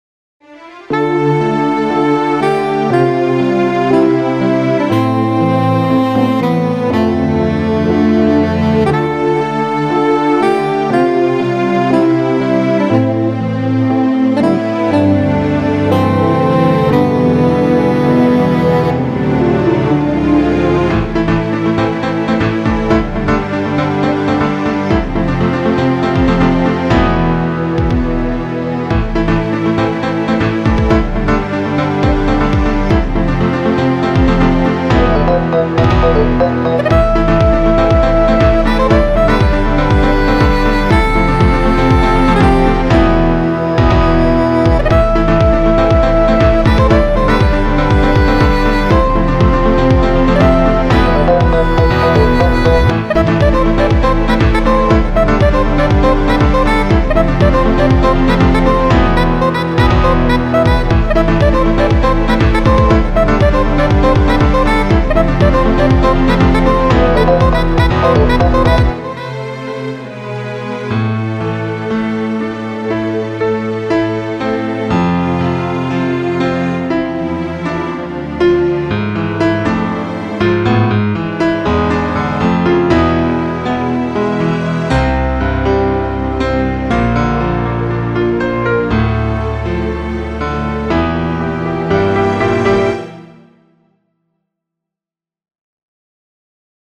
שיר אינסטרומנטלי - בסגנון סוער ואנרגטי!